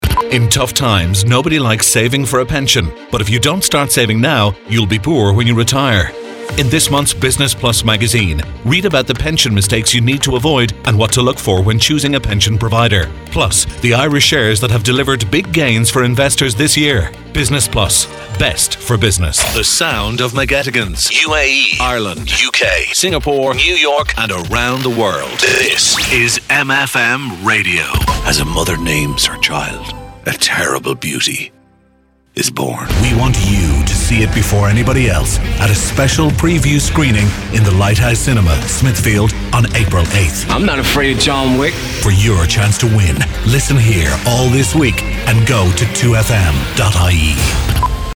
Male
40s/50s, 50+
Irish Dublin Neutral, Irish Neutral